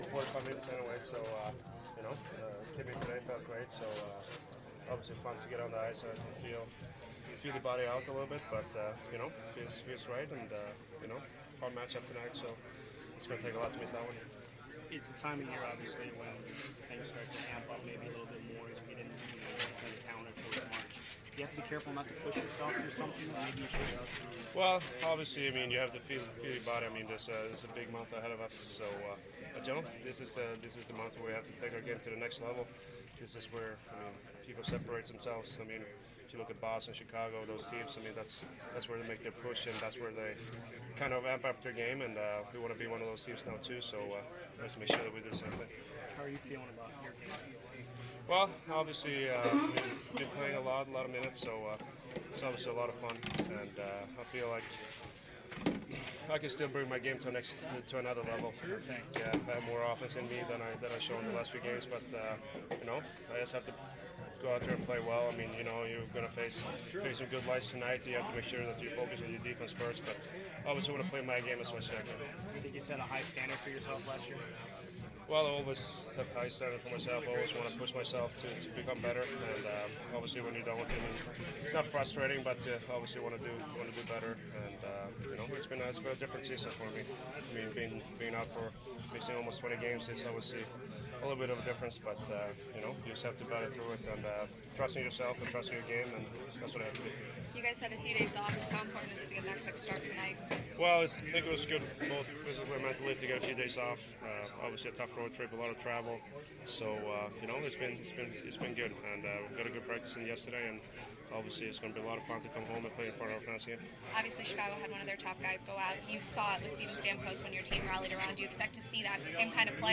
Hedman post morning skate 2/27/15